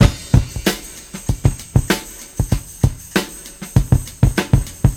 97 Bpm Drum Groove A# Key.wav
Free breakbeat sample - kick tuned to the A# note.
.WAV .MP3 .OGG 0:00 / 0:05 Type Wav Duration 0:05 Size 857,59 KB Samplerate 44100 Hz Bitdepth 16 Channels Stereo Free breakbeat sample - kick tuned to the A# note.
97-bpm-drum-groove-a-sharp-key-jn3.ogg